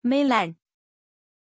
Aussprache von Milàn
pronunciation-milàn-zh.mp3